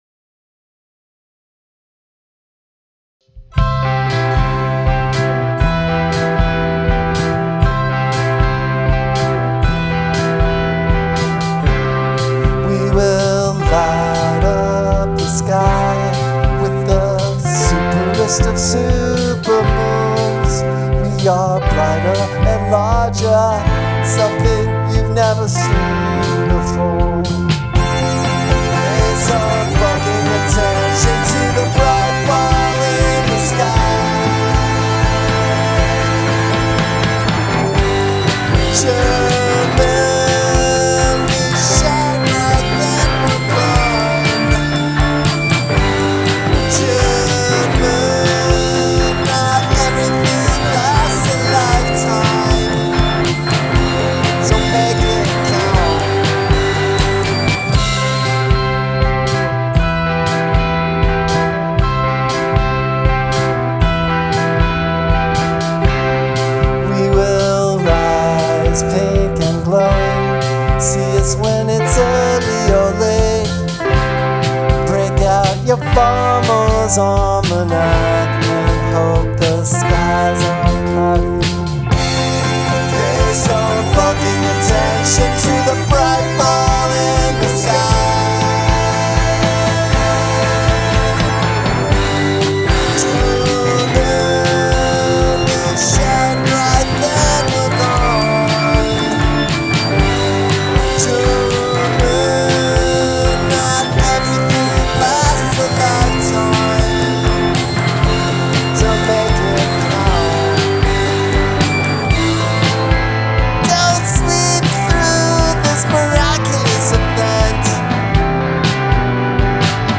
drums
e-bow solo